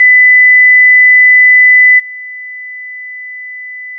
- 空気伝播音の場合 -
《基準壁》からの音 2秒 →《基準壁+ノイズクリア》からの音 2秒